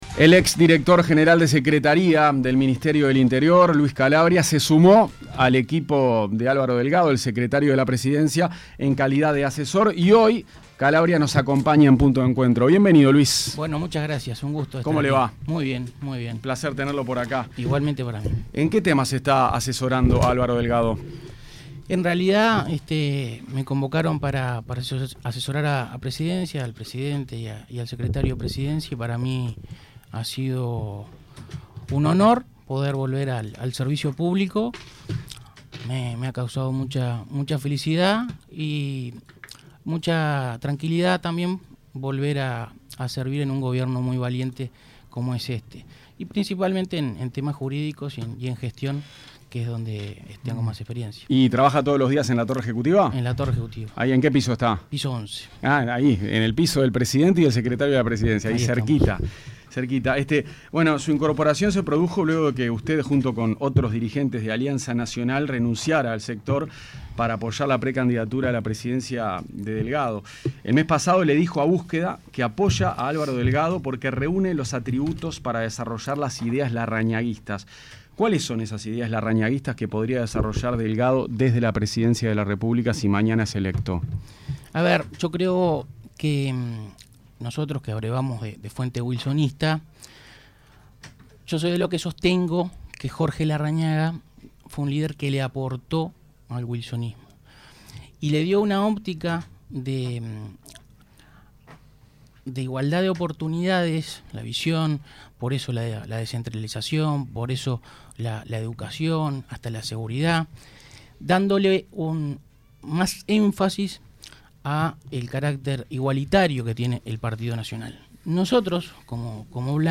Entrevista-a-Luis-Calabria.mp3